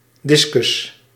Ääntäminen
Synonyymit plaat schijf Ääntäminen Tuntematon aksentti: IPA: /ˈdɪskʏs/ Haettu sana löytyi näillä lähdekielillä: hollanti Käännöksiä ei löytynyt valitulle kohdekielelle.